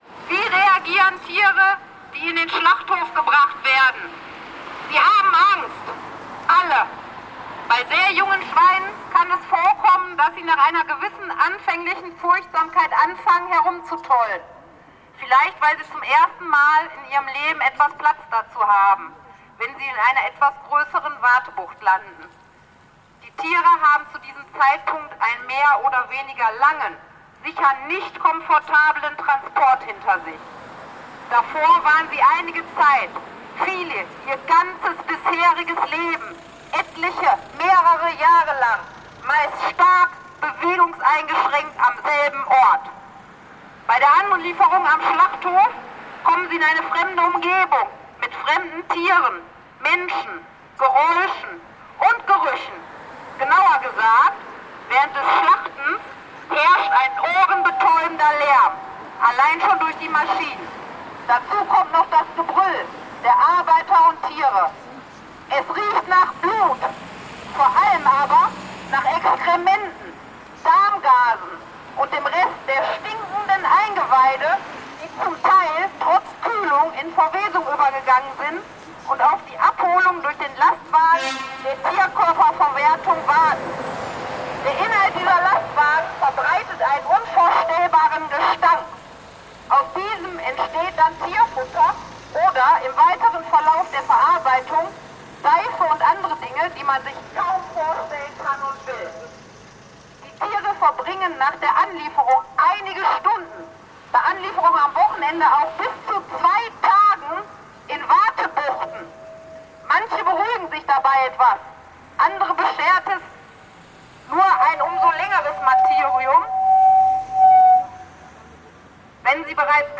Schlachthof-Mahnwache Tönnies in Rheda-Wiedenbrück
Ansprache 1 an Tönnies